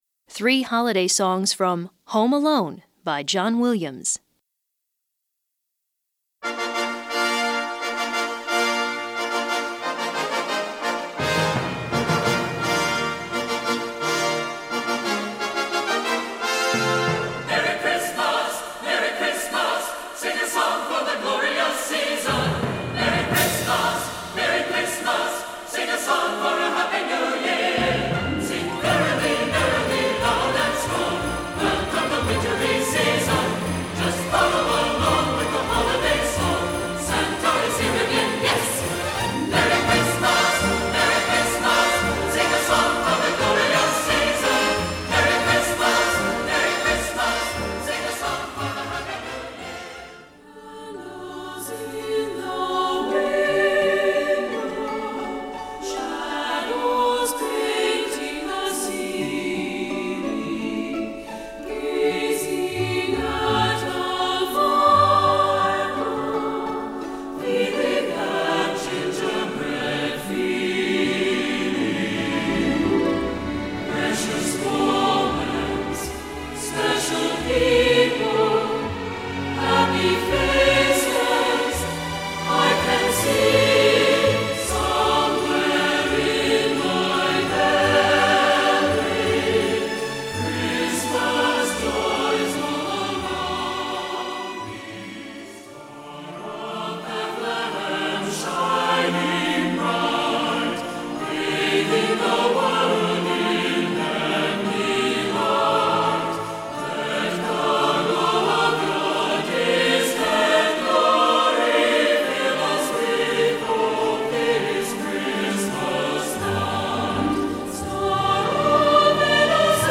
Chant Mixtes